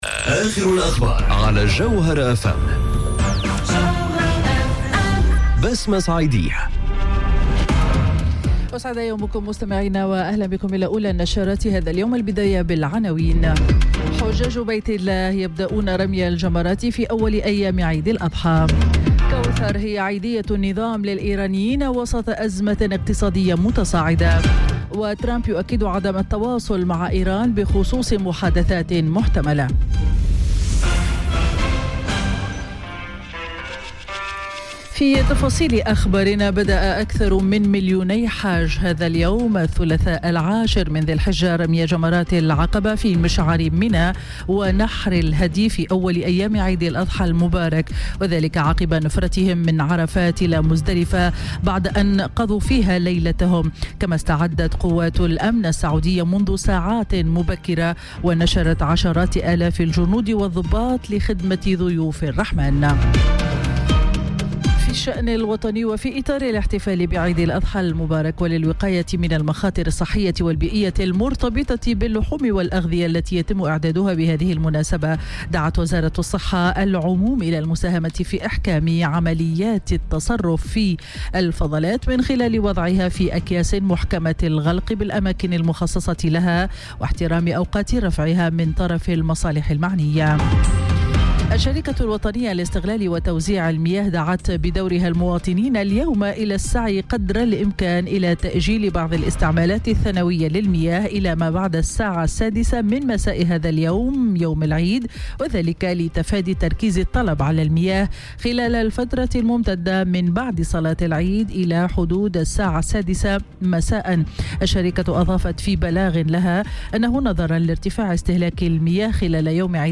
نشرة أخبار السابعة صباحا ليوم الثلاثاء 21 أوت 2018